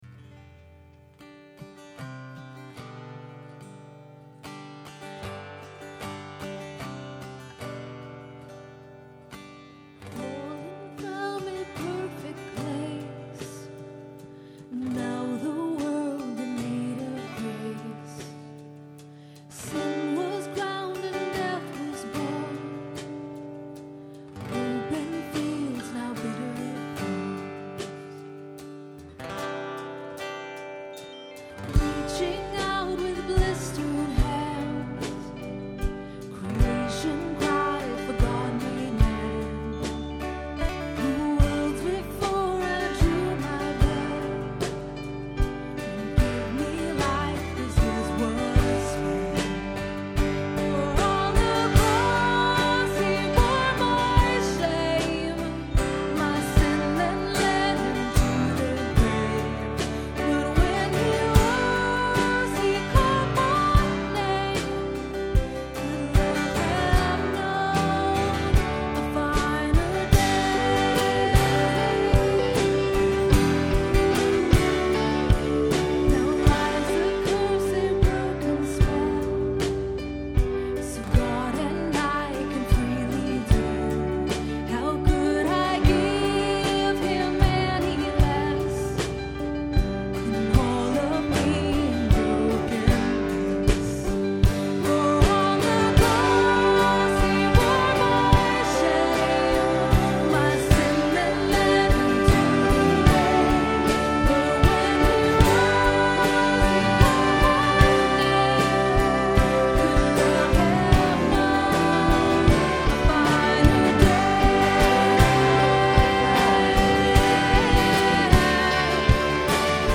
Performed live on Easter at Terra Nova - Troy on 4/12/09.